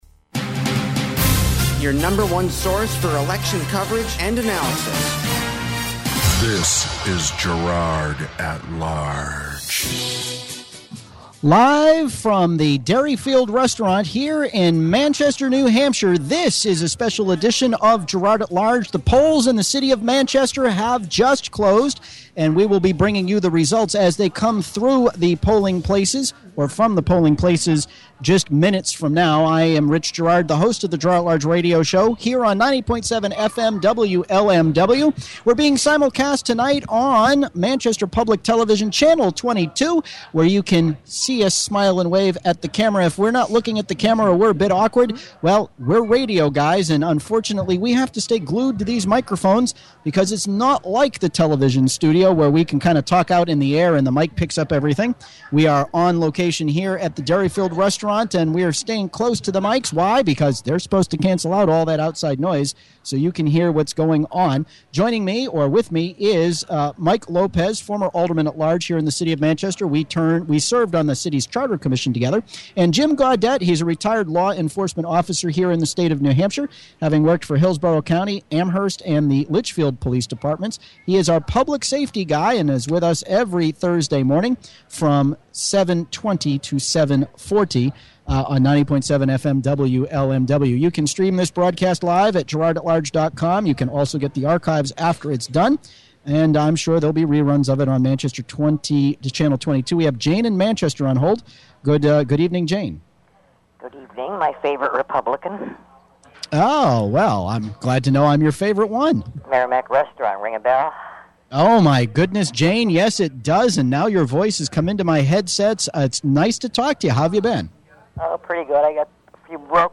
Live Election Night coverage. The issues, the candidates and the campaigns were discussed before election results starting coming in, fast and furious.